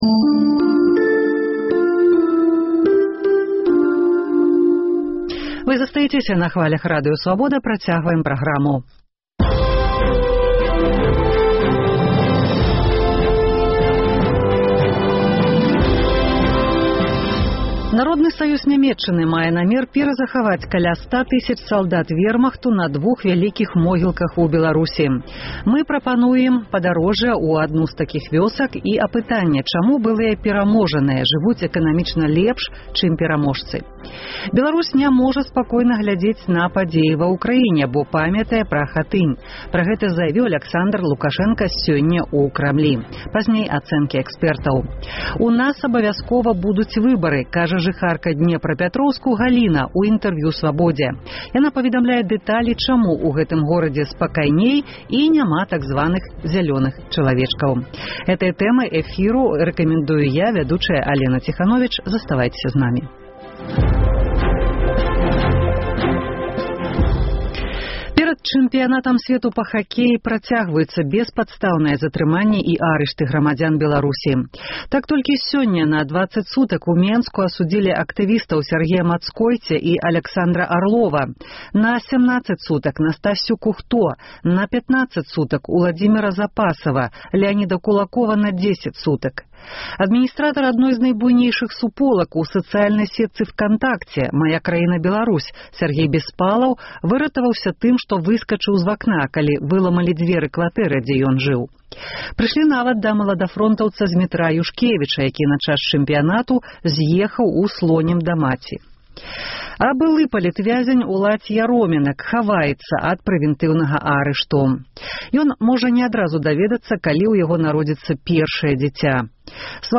Як выглядаюць сёньня пахаваньні салдат вэрмахту ў Беларусі. Рэпартаж зь вёскі Шчаткава пад Бабруйскам. Чаму пераможаная Нямеччына жыве лепш, чым краіны былога СССР, які перамог у вайне? Апытаньне ў Гомелі. Вы можаце адказваць на гэтае пытаньне ў эфіры.